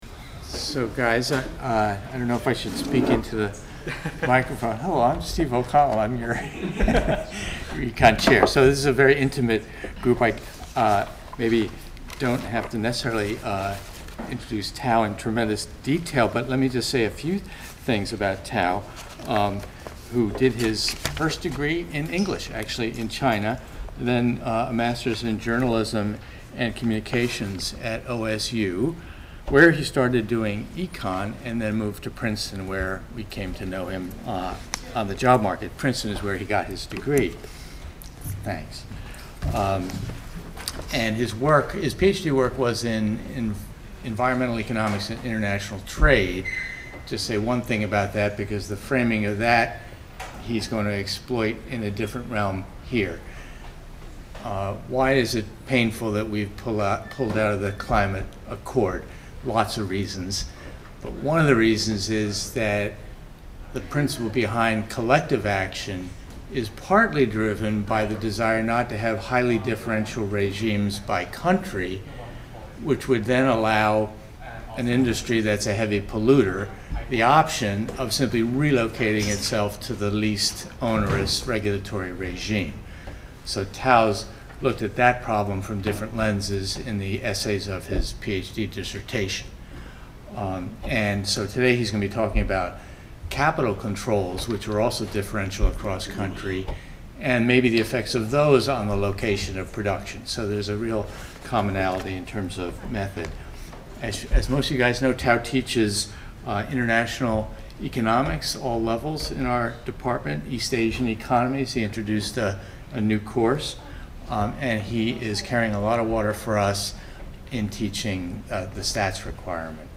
Faculty_Lecture_May_7th.mp3